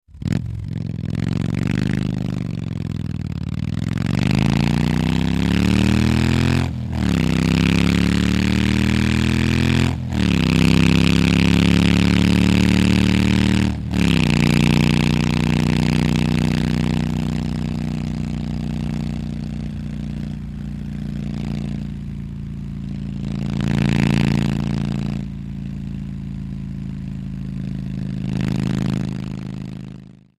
Harley Motorcycle On Board At Various Speeds, With Gear Shifts